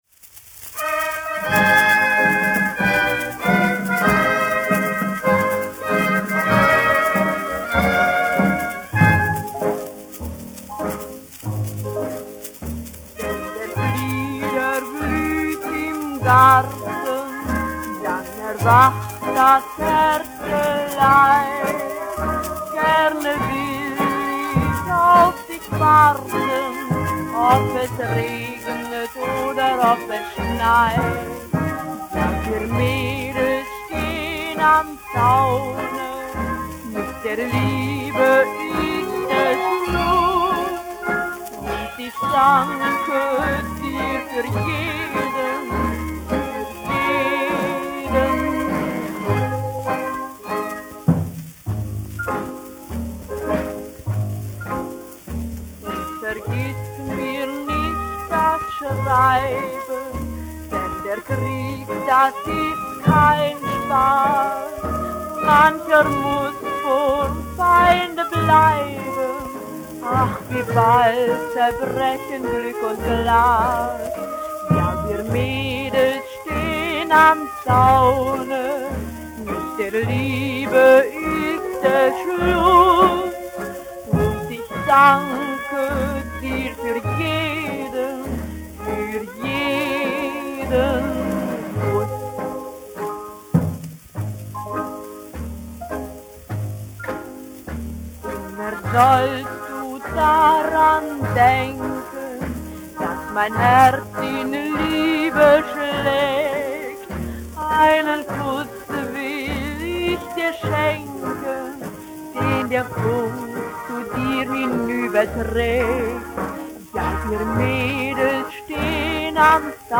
1 skpl. : analogs, 78 apgr/min, mono ; 25 cm
Populārā mūzika
Skaņuplate
Latvijas vēsturiskie šellaka skaņuplašu ieraksti (Kolekcija)